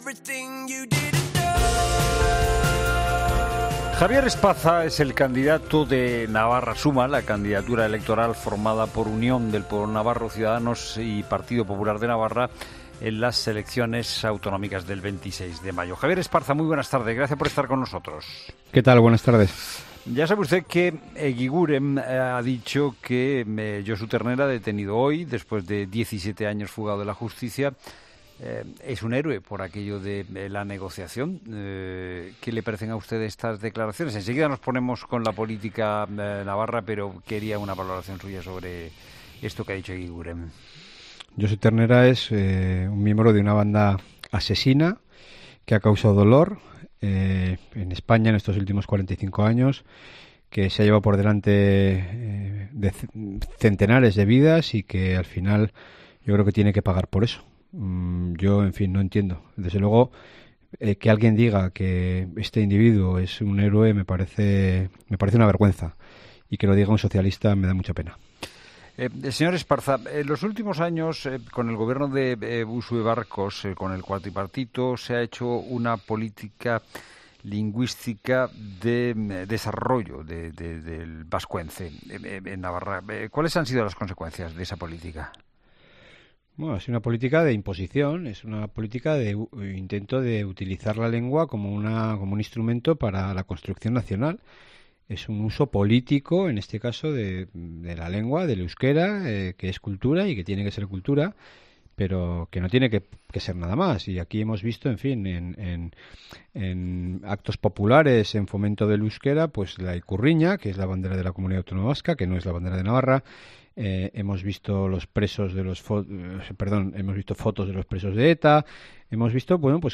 El candidato de Navarra Suma, Javier Esparza, ha criticado en los micrófonos de La Tarde el uso político del lenguaje del Gobierno de Uxue Barkos en Navarra y ha anunciado que si es presidente tras las elecciones del próximo 26 de mayo derogará  el decreto de acceso a la función pública.